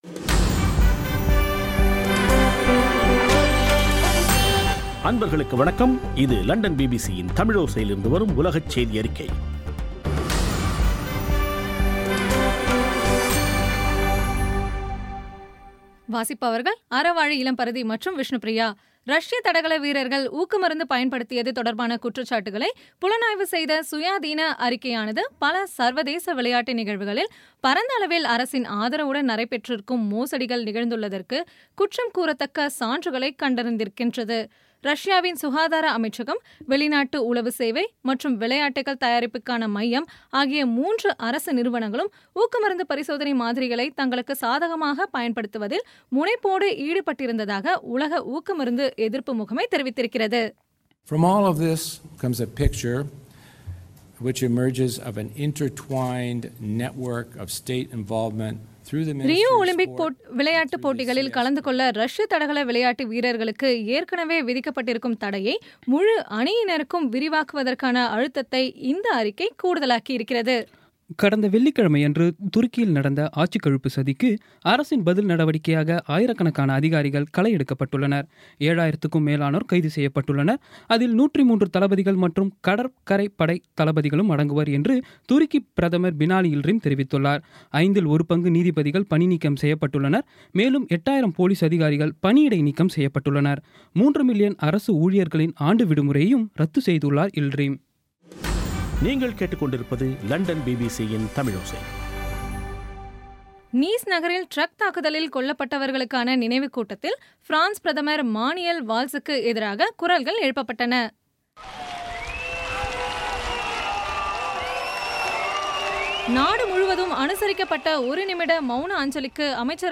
இன்றைய (ஜூலை 18ம் தேதி ) பிபிசி தமிழோசை செய்தியறிக்கை